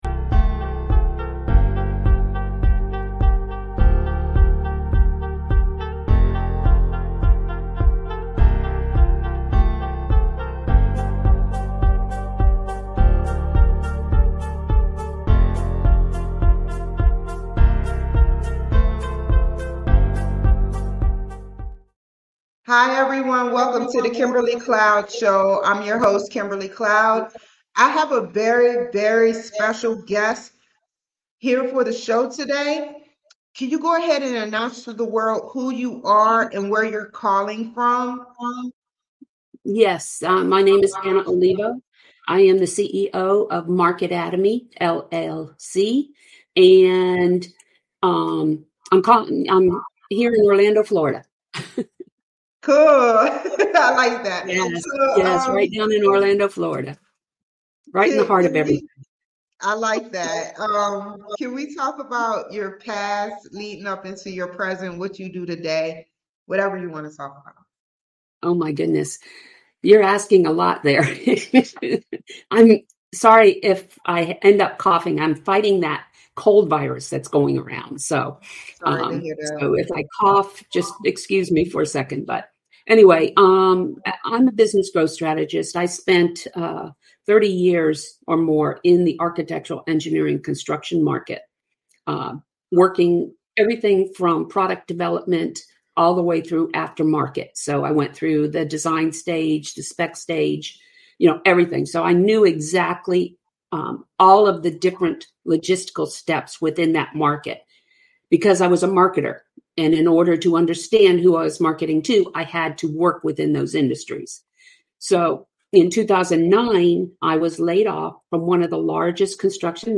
Talk Show Episode
No matter what arena you are in just know I am here to interview you and get YOUR STORY!!!